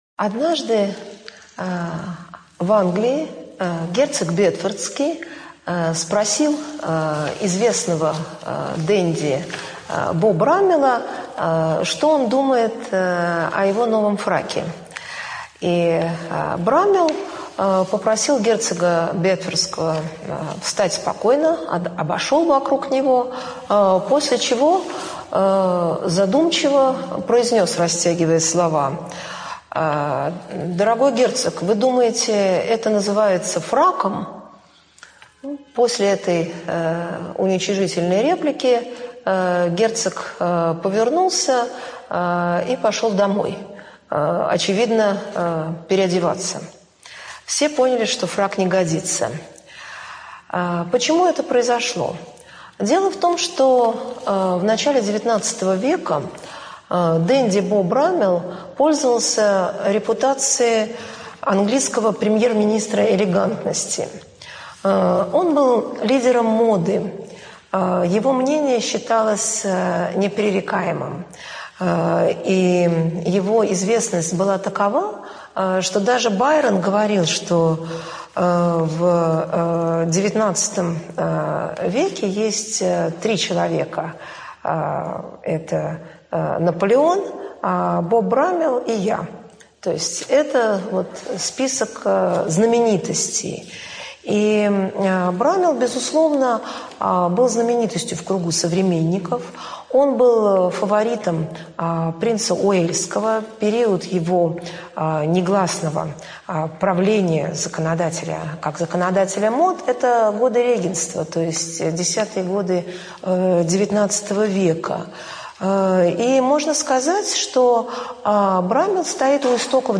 ЧитаетАвтор
Студия звукозаписивимбо